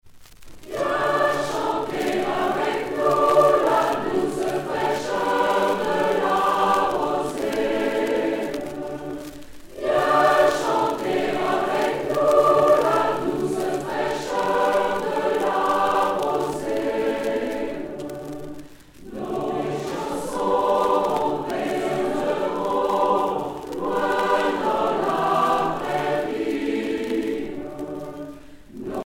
Rassemblement des chorales A Coeur Joie
Pièce musicale éditée